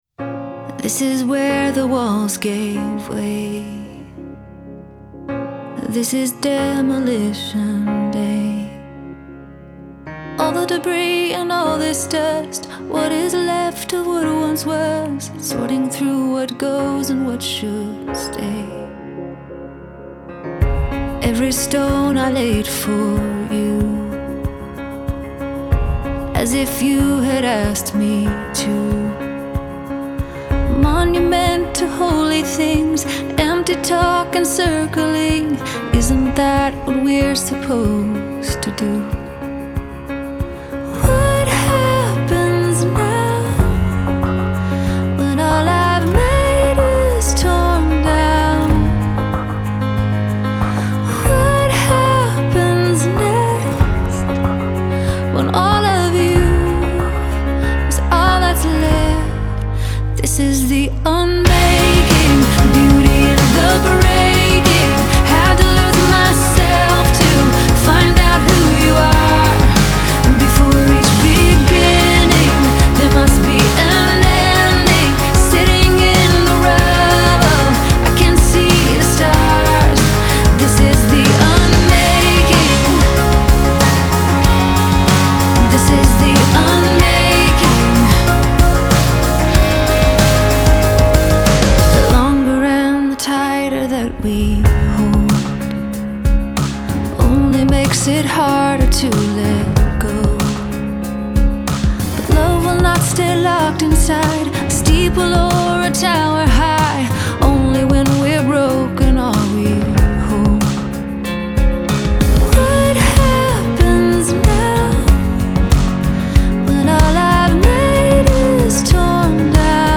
Genre: CCM, Pop, Pop Rock